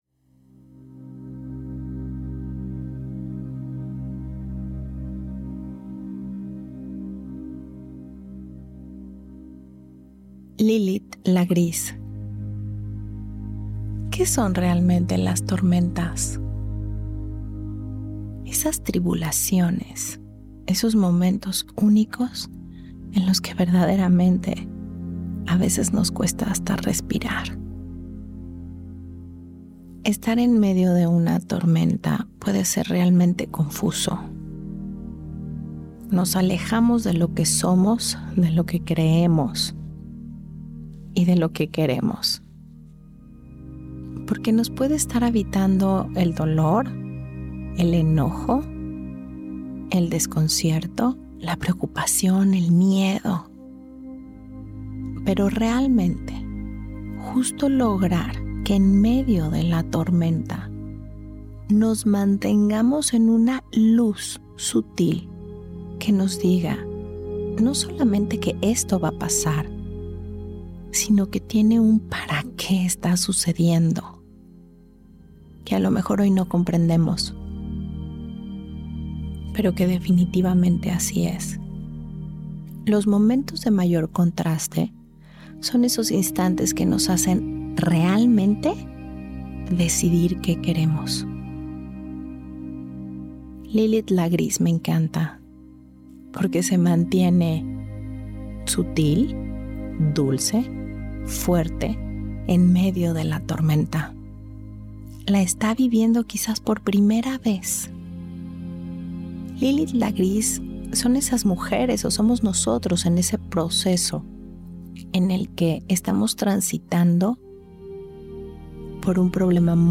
Esta meditación nos invita a conectar sin miedo con lo que realmente somos y queremos.